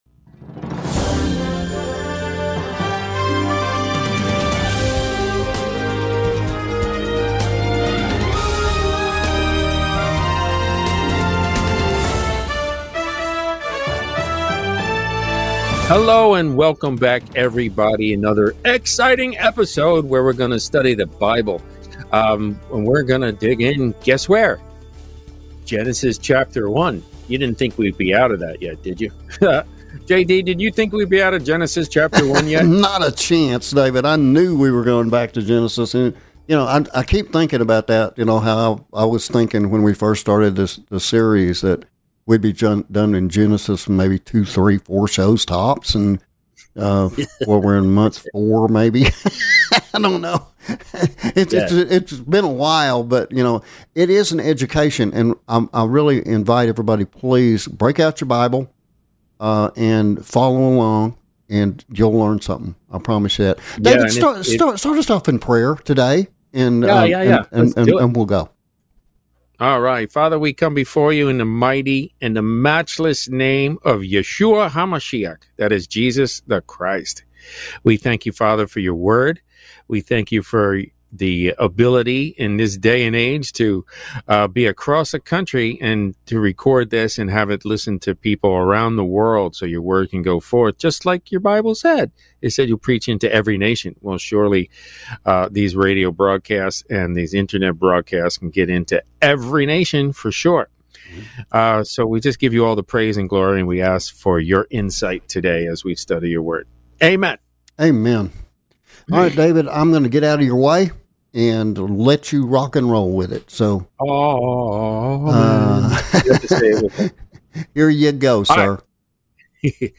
The "Last Christian" is Presented every Tuesday, Thursday and Saturday evenings at 7:30pm Central on more than 50 Radio Stations, and broadcast to all 50 US States and more than 160 Countries around the World.